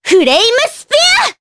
Cleo-Vox_Skill1_jp.wav